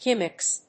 /ˈɡɪm.ɪks(英国英語), ˈgɪmɪks(米国英語)/